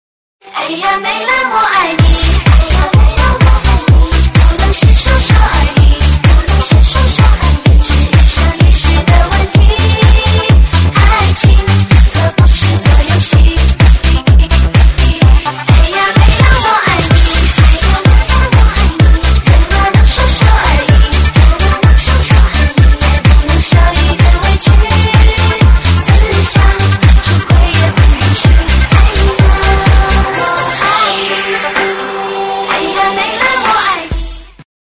DJ铃声 大小
DJ舞曲